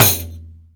RIQ 2A.WAV